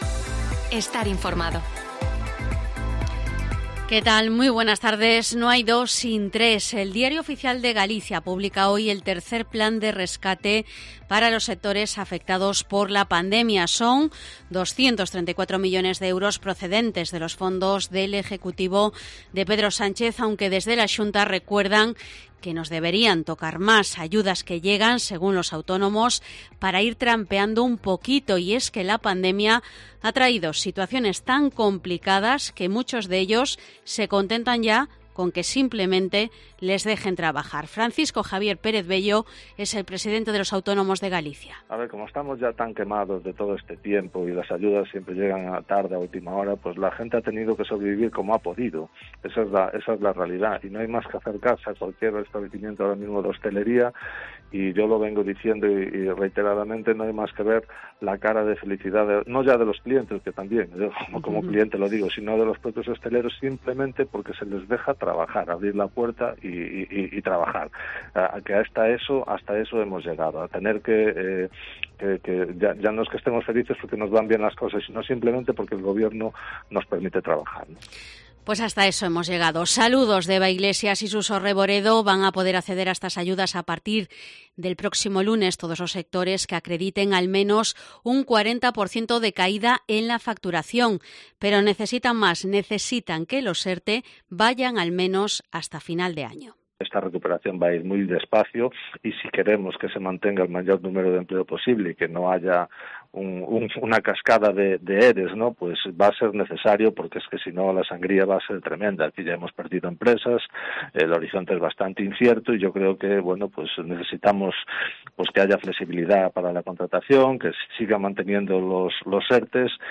Informativo mediodía COPE Coruña 11/06/2021 De 14:20 a 14:30 horas